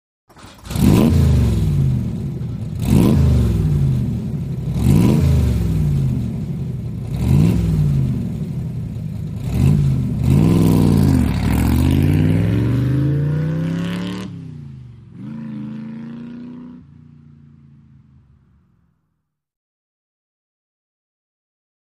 Cobra; Start / Away; Growling Start And Revs, Medium Away With Gears Shifting In Distance. Paved Surface. Close To Distant Perspective. Sports Car, Auto.